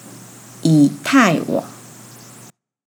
イー タイ ワン
yǐ tài wǎng